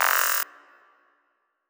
/audio/sounds/Extra Packs/Dubstep Sample Pack/FX/